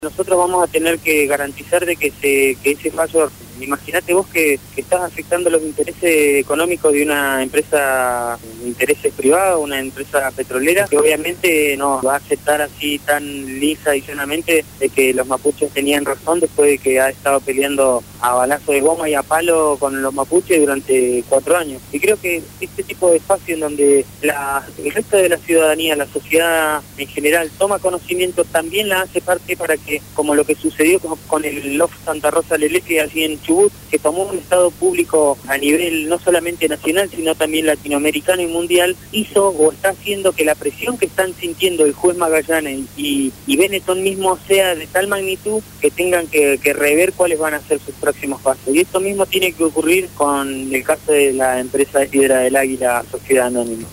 fue entrevistado en el programa “Abramos la boca” (Lunes a viernes 16 a 18 hs.) por Radio Gráfica.